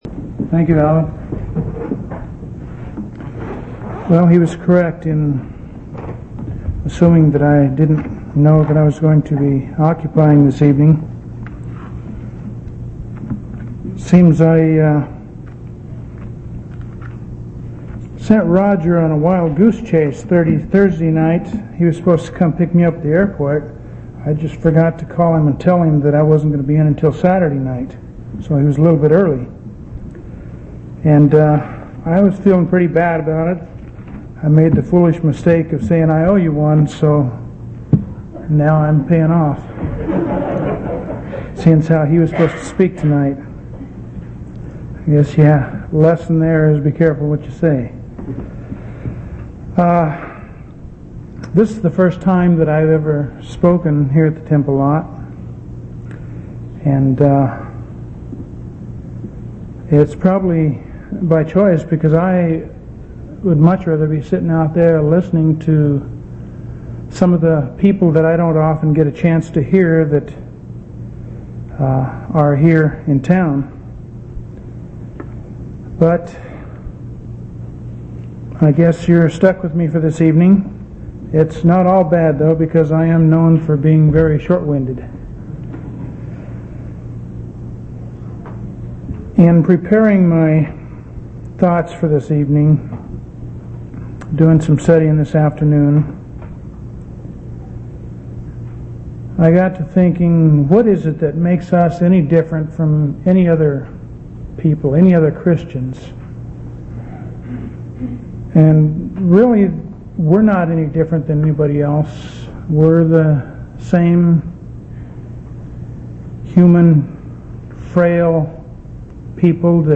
8/24/1986 Location: Temple Lot Local Event